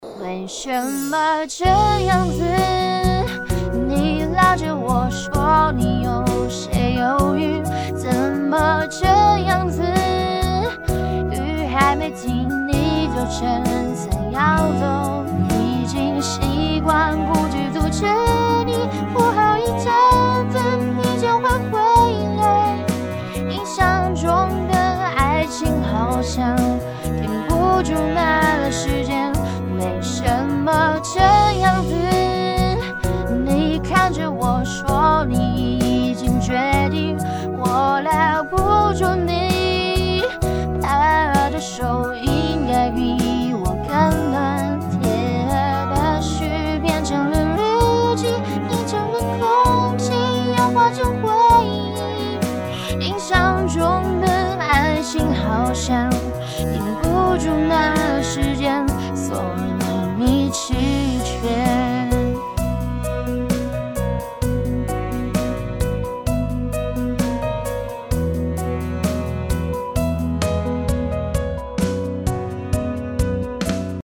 叁模型RVC优化版、SVC、Bert-VITS 2模型 台配版柯南声音模型
因此，他的声音虽然听起来是一个小孩的声音，但却没有一般小孩的那种天真烂漫，反而显得相对成熟稳重，同时又保持着一定的高亢和清脆，凸显出他孩童外表下的成熟内心。
RVC模型效果